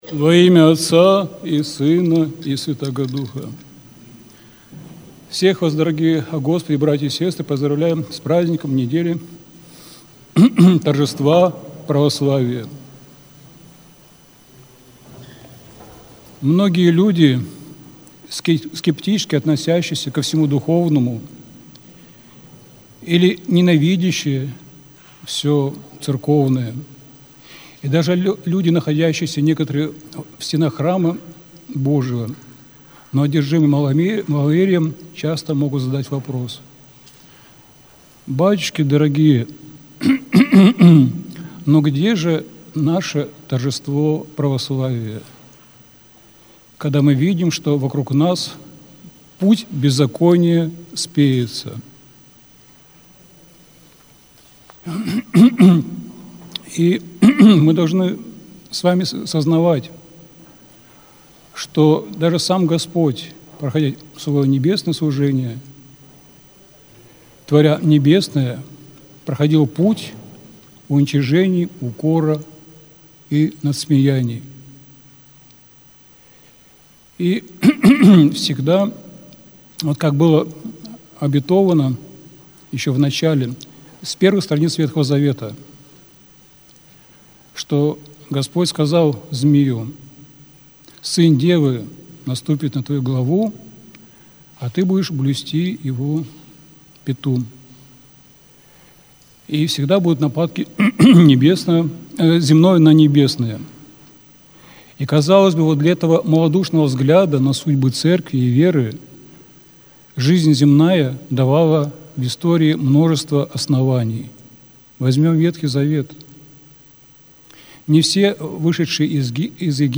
Аудиозапись проповеди